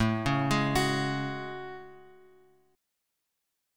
Listen to AM13 strummed